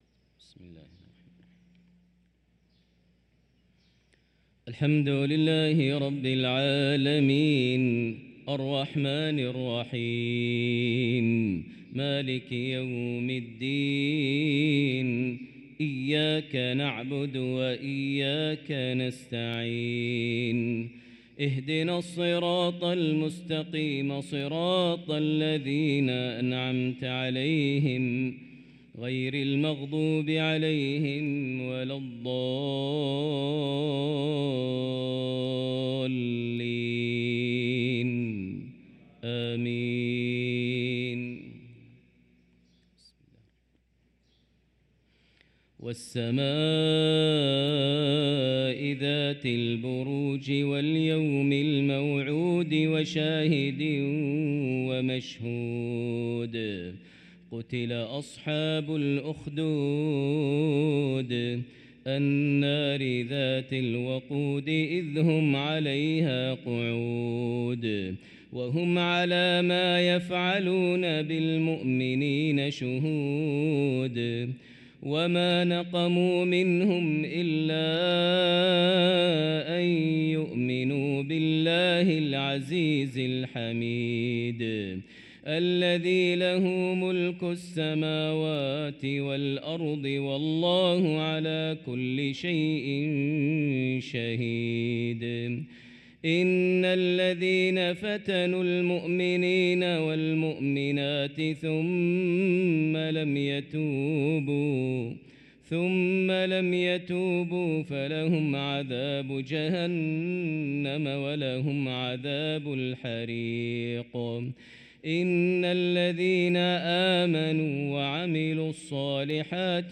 صلاة المغرب للقارئ ماهر المعيقلي 15 ربيع الآخر 1445 هـ
تِلَاوَات الْحَرَمَيْن .